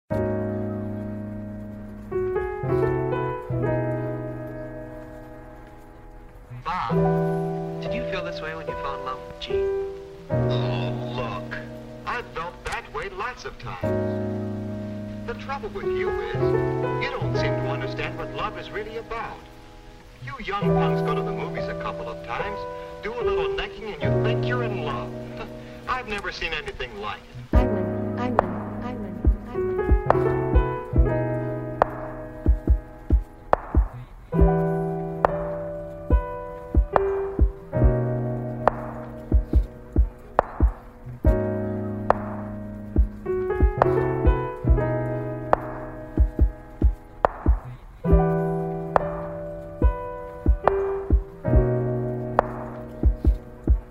Tu veux avancer, mais tu sens que tout s’emmêle.